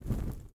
Flare02.ogg